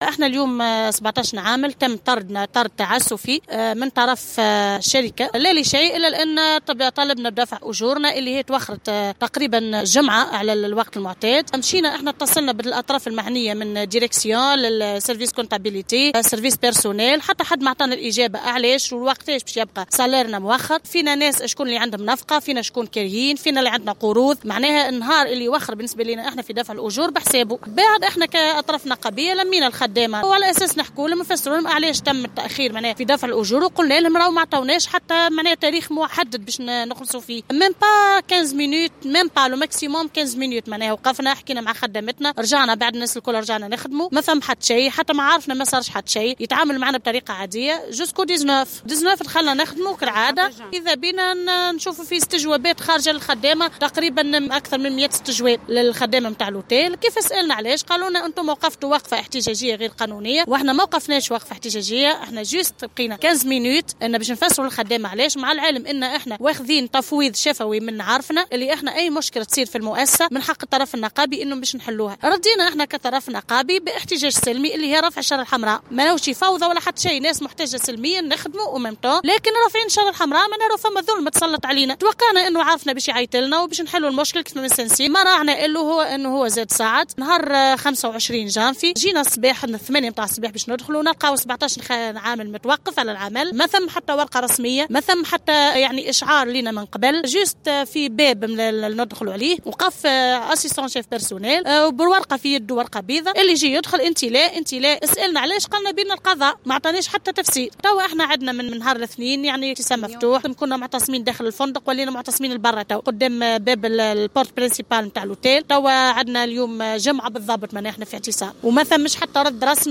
في تصريح لمراسلة "الجوهرة أف أم" بأنه تم إيقافهم عن العمل بعد مطالبتهم بتوضيح سبب التأخر الحاصل في صرف أجورهم.